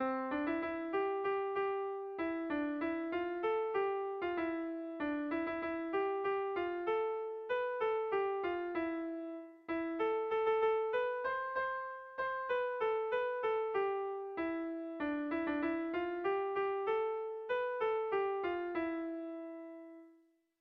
Sentimenduzkoa
AB1DB2